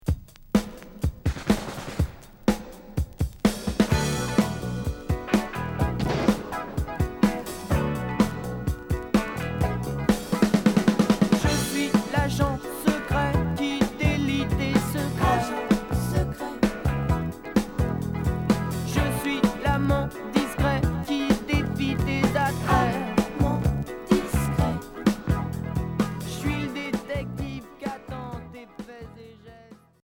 Rock new wave Troisième 45t retour à l'accueil